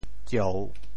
醮 部首拼音 部首 酉 总笔划 19 部外笔划 12 普通话 jiào 潮州发音 潮州 zieu3 文 中文解释 醮〈名〉 古冠、婚礼所行的一种简单仪式。